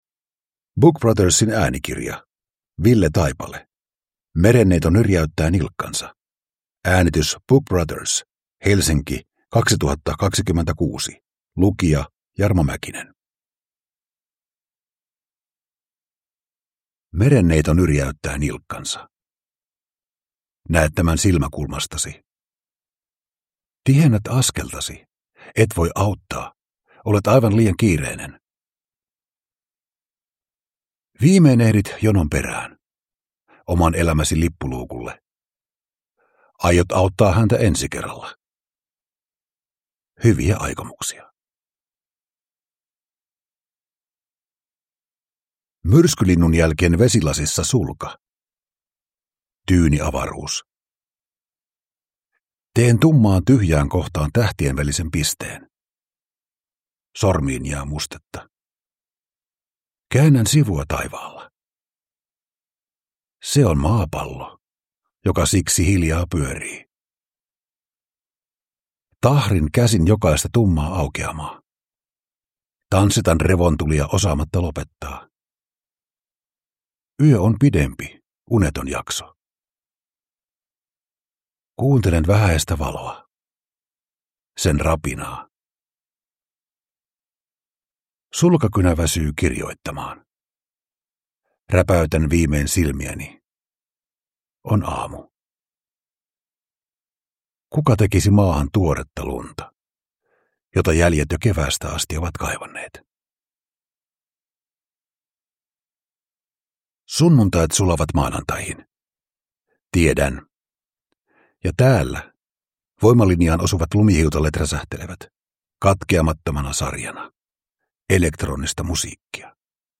Merenneito nyrjäyttää nilkkansa – Ljudbok